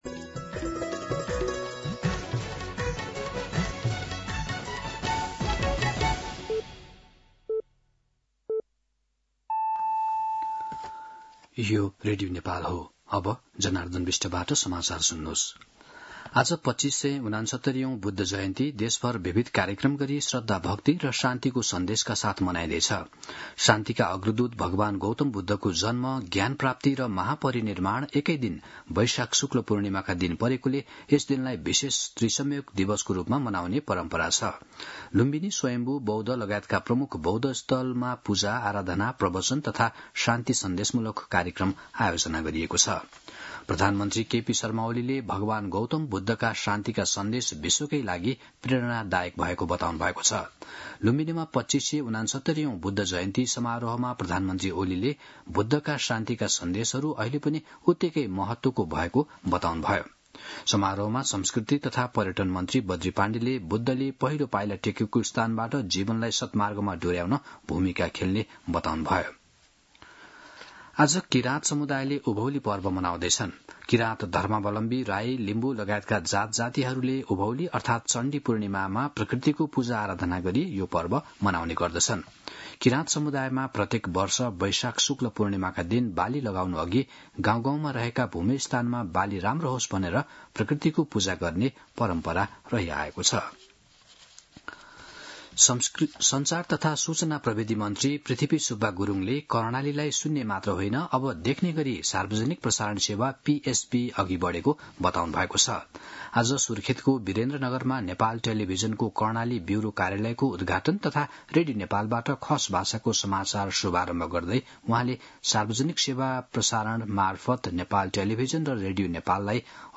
दिउँसो १ बजेको नेपाली समाचार : २९ वैशाख , २०८२
1-pm-Nepali-News-01-29.mp3